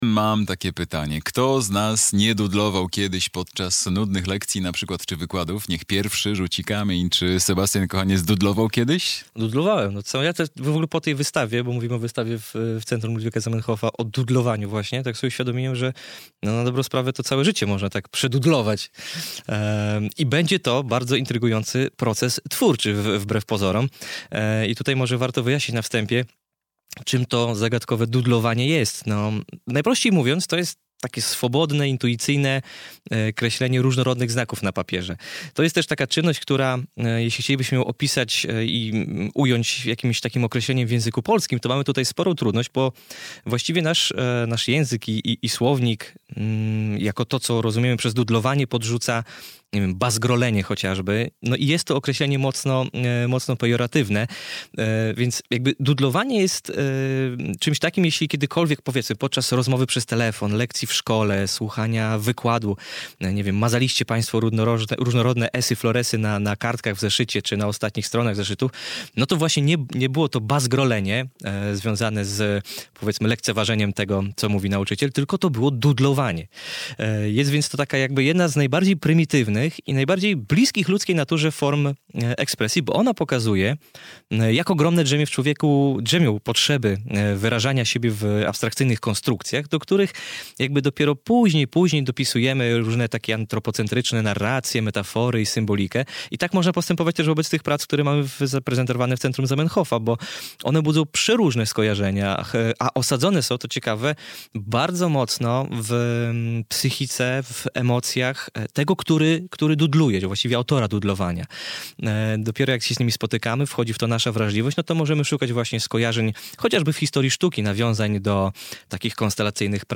recenzja